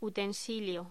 Locución: Utensilio